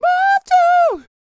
DD FALSET032.wav